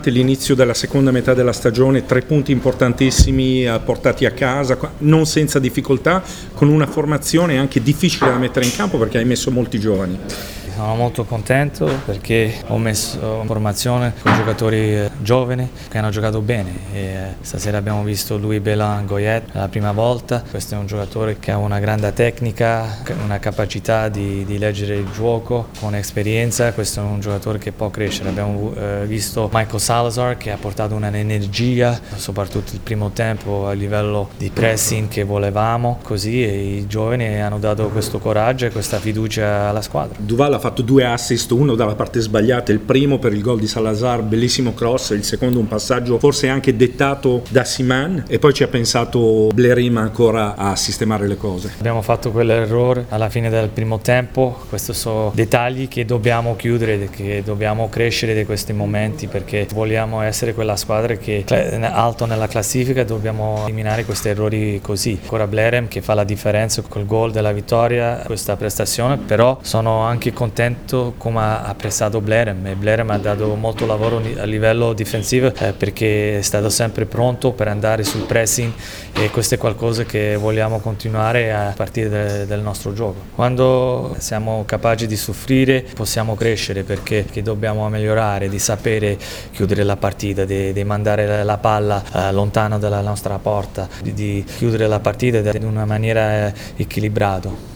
Le interviste post-partita –